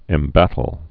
(ĕm-bătl)